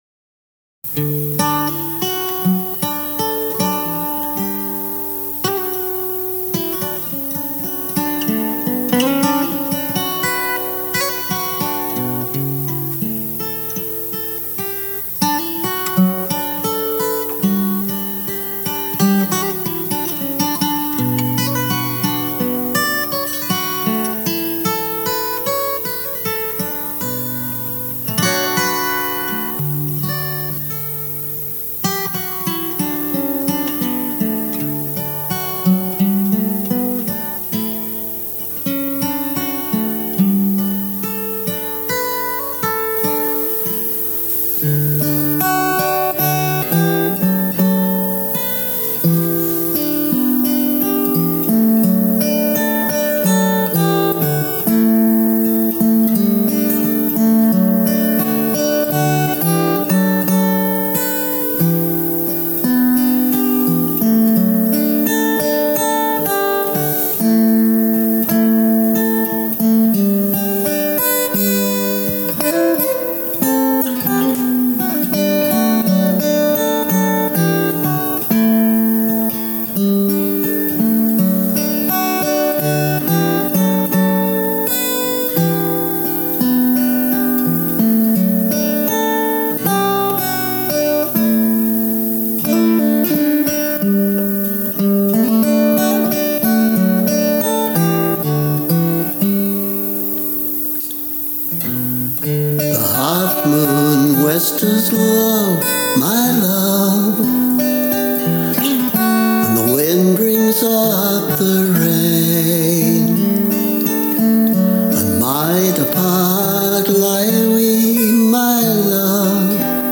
Remastered for volume: